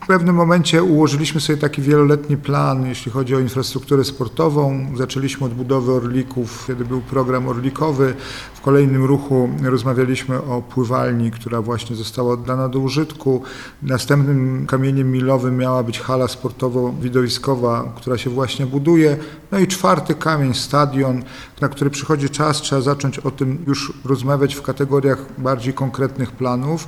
– Działamy według opracowanego planu inwestycyjnego – powiedział prezydent Jacek Milewski: